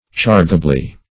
\Charge"a*bly\